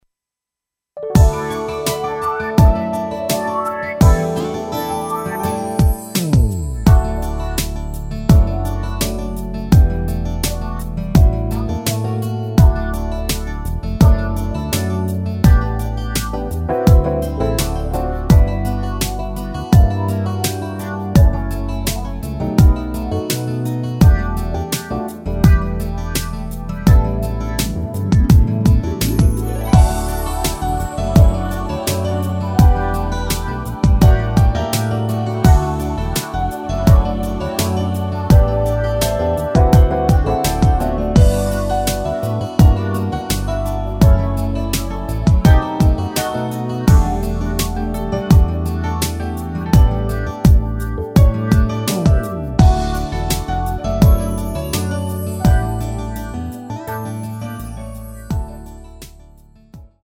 랩부분 삭제한 편곡 MR 입니다.
◈ 곡명 옆 (-1)은 반음 내림, (+1)은 반음 올림 입니다.
앞부분30초, 뒷부분30초씩 편집해서 올려 드리고 있습니다.
중간에 음이 끈어지고 다시 나오는 이유는